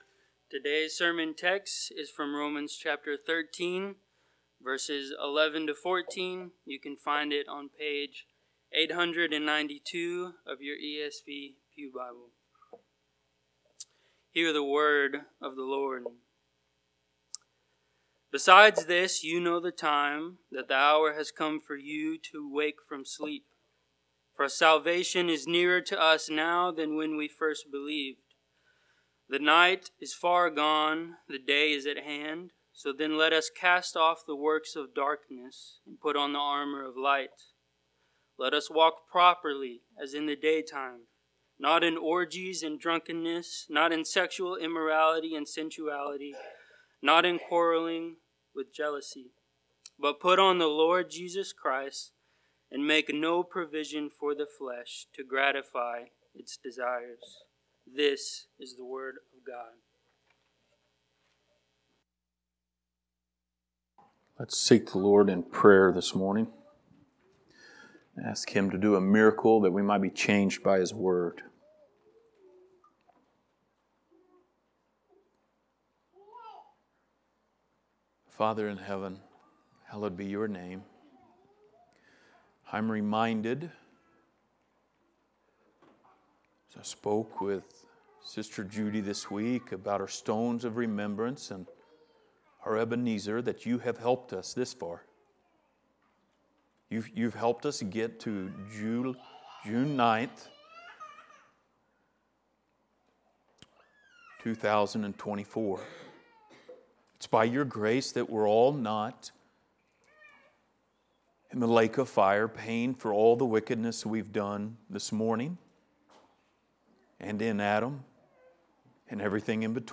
Passage: Romans 13:11-14 Service Type: Sunday Morning